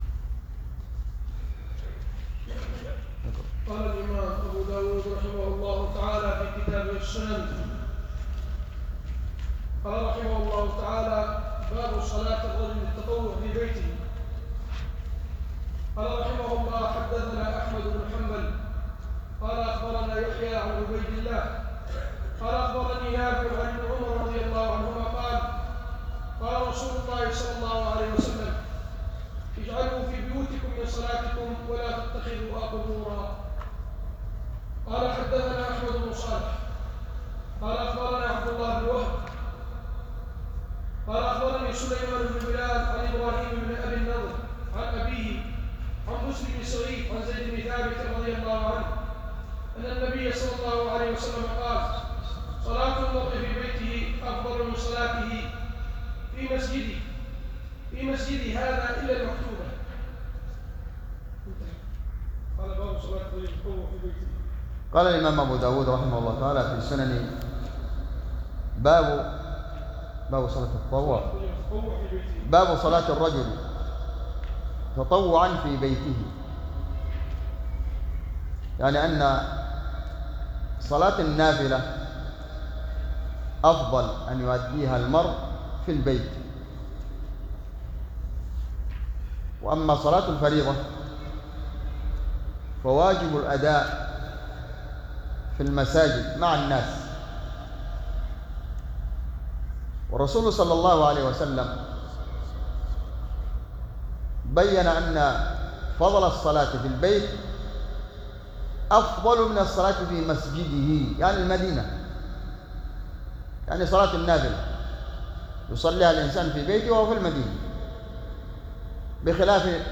بجامع الدرسي صبيا